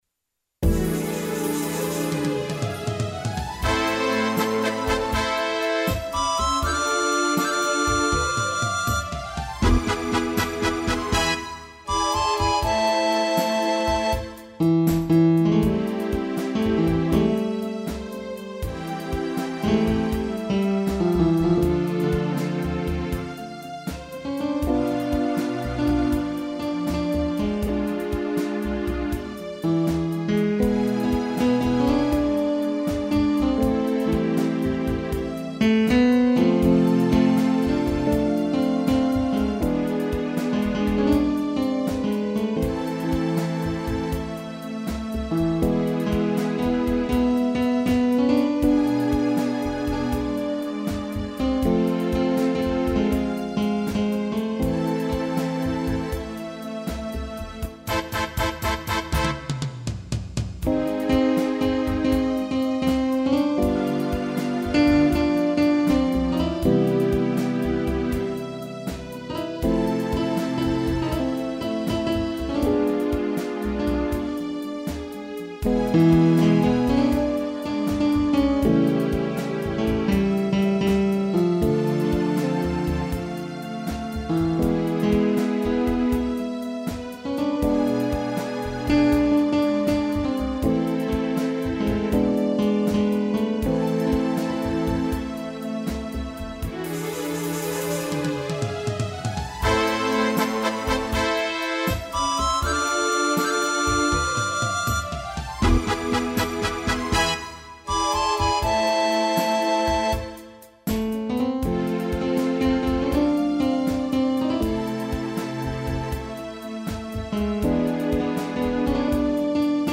piano e gaita
instrumental